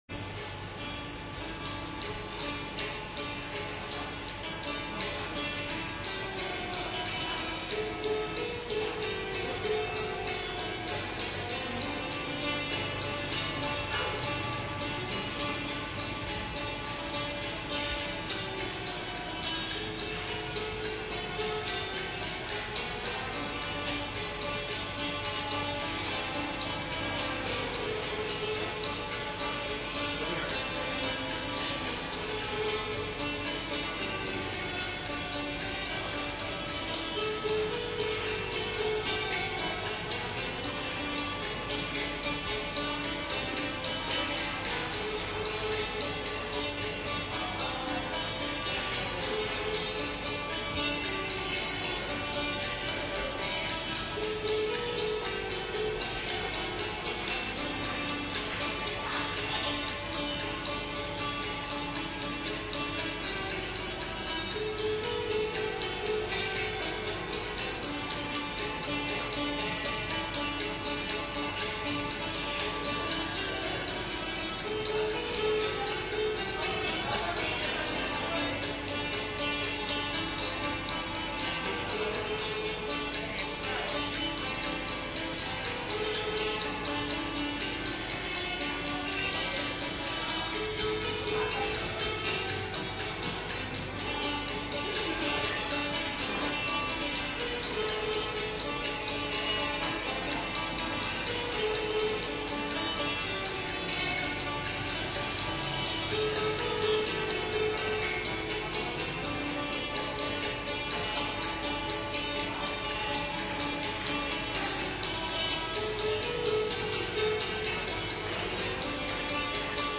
In the evening, two ladies played Thai music in the lobby.
ThaiSong4.mp3